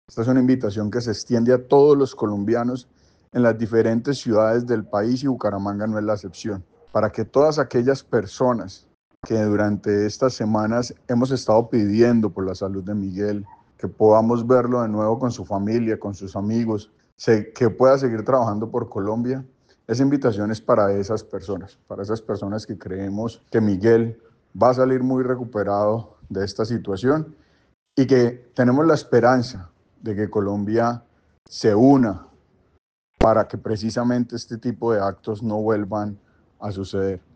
Óscar Villamizar, representante a la Cámara